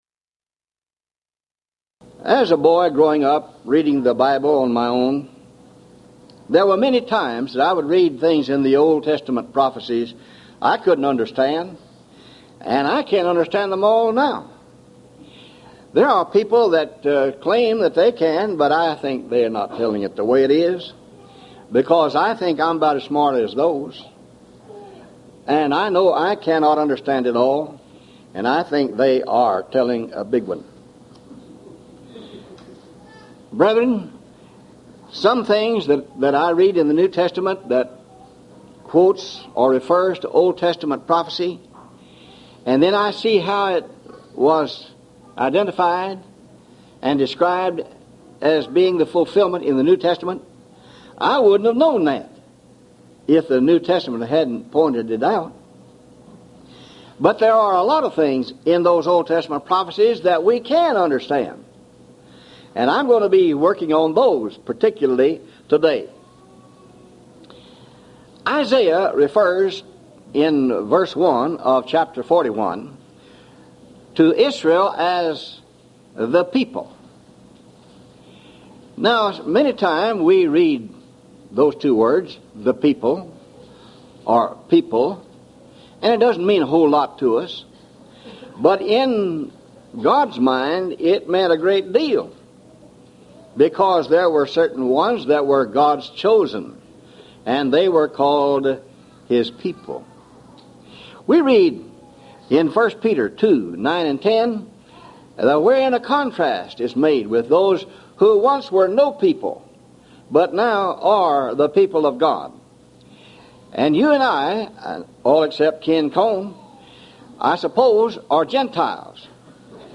Series: Houston College of the Bible Lectures Event: 1996 HCB Lectures